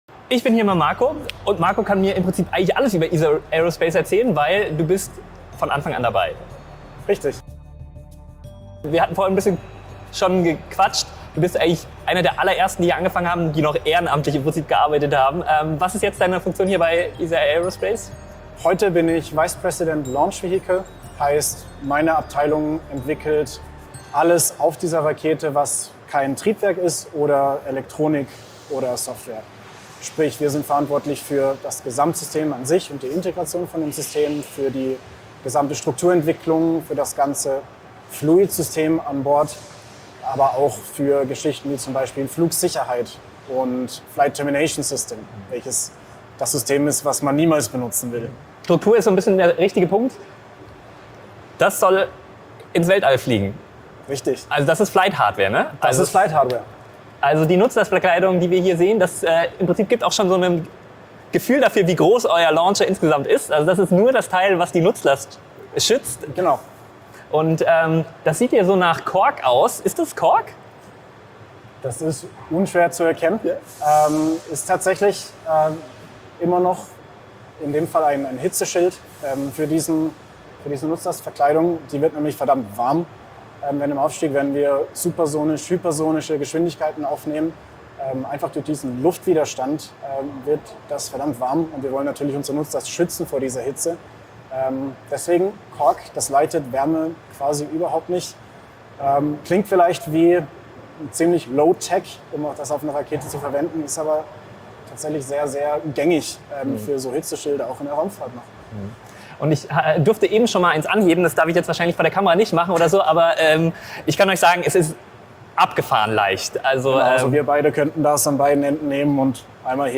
Warum sollte man Luft- und Raumfahrttechnik studieren? Wir waren einen ganzen Tag bei Isar Aerospace und neben Interviews konnten wir uns die moderne Fertigung ansehen und neben Triebwerken auch Teile für den ersten Flug der Spectrum-Rakete bestaunen.